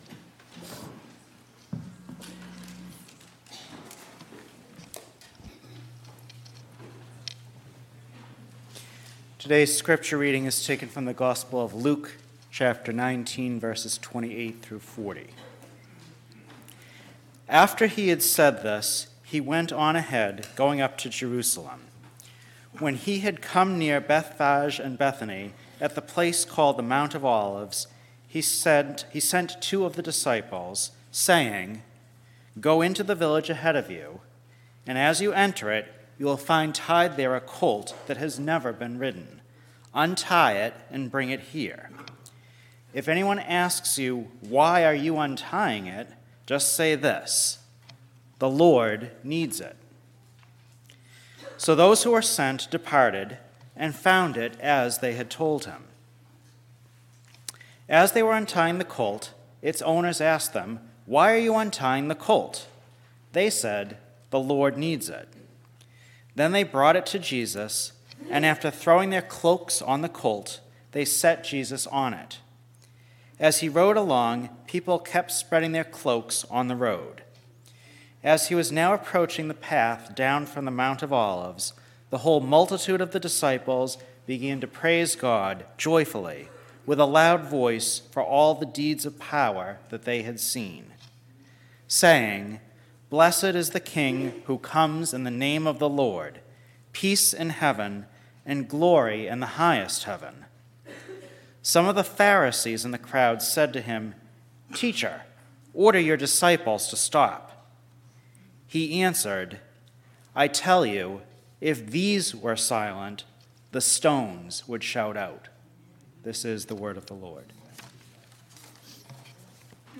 Scripture-Reading-and-Sermon-Apr-2-2023.mp3